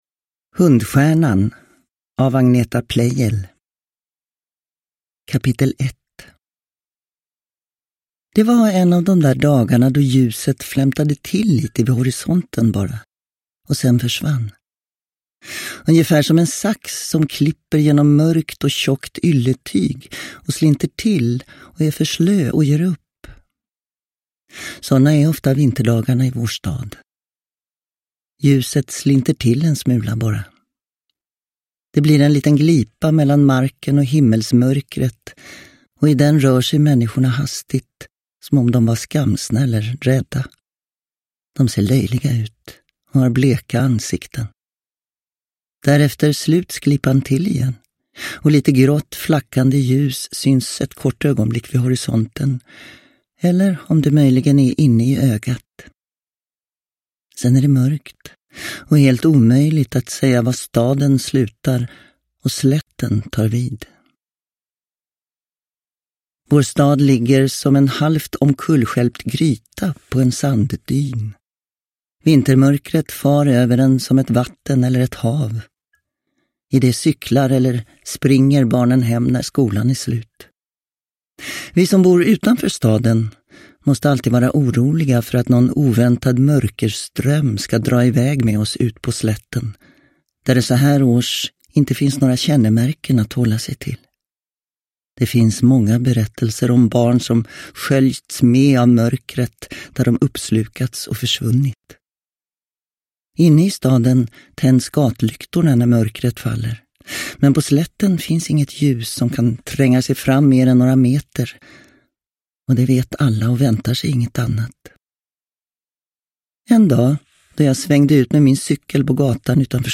Hundstjärnan – Ljudbok – Laddas ner
Uppläsare: Gunnel Fred